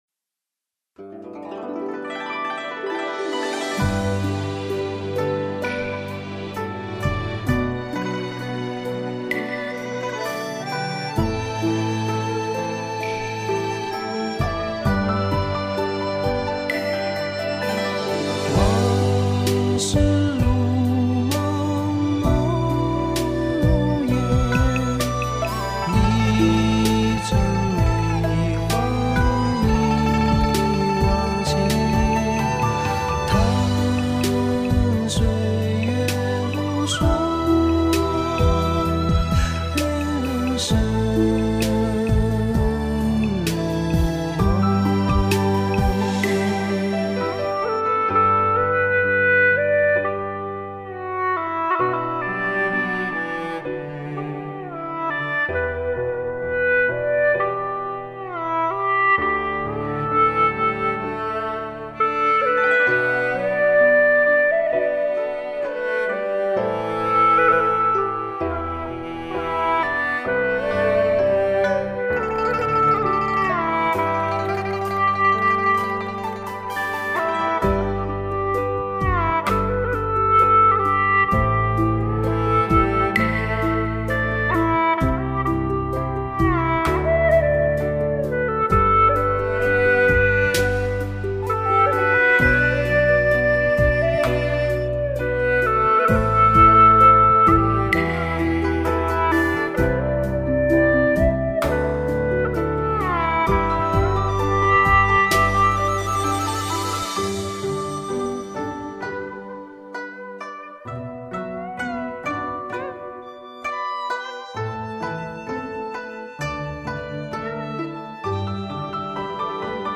G调 曲类 : 独奏
它只有平淡清雅的旋律